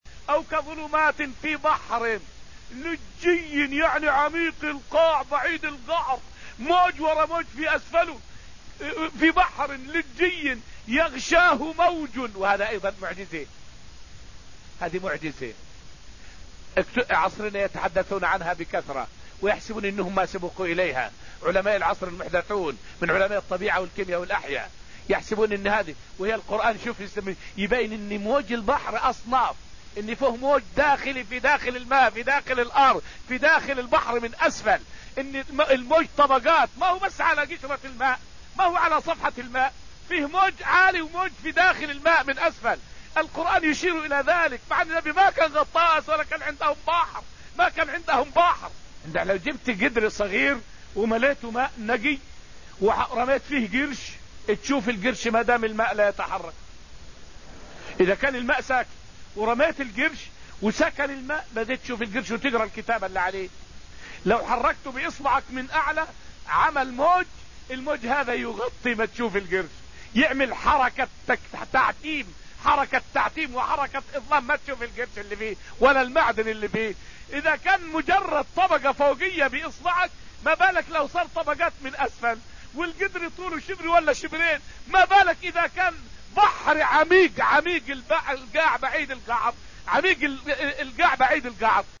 فائدة من الدرس التاسع من دروس تفسير سورة الحديد والتي ألقيت في المسجد النبوي الشريف حول معنى قوله تعالى: {أو كظلمات في بحر لجي}.